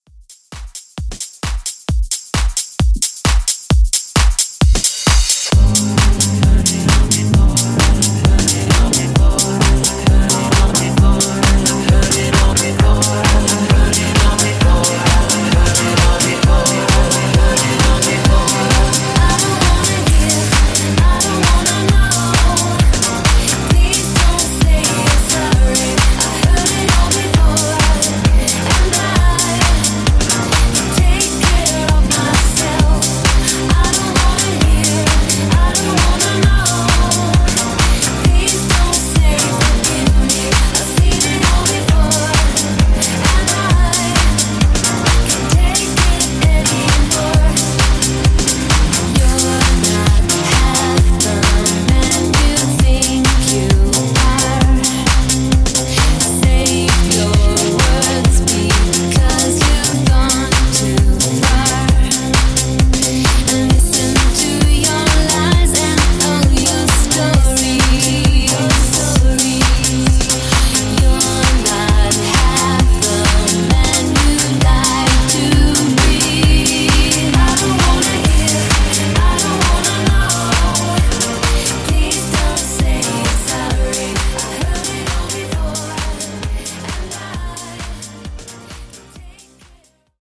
Extended Mix)Date Added